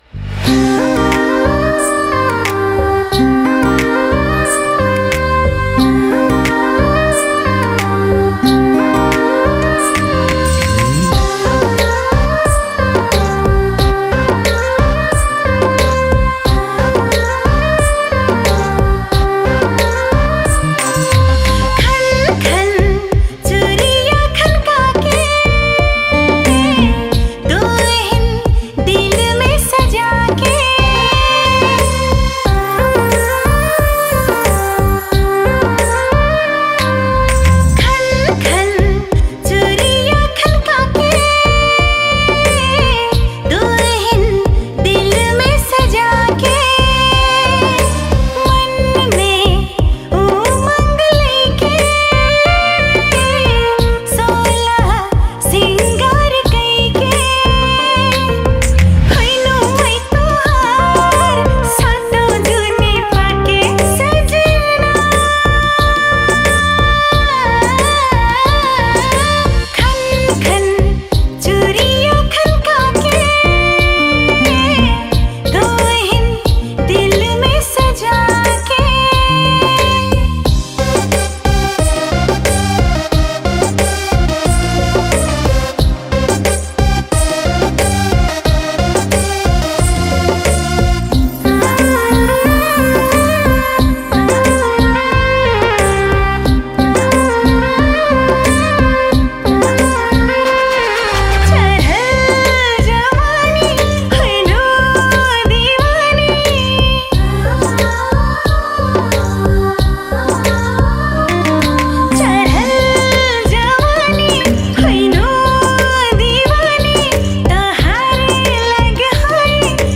Tharu Romantic Song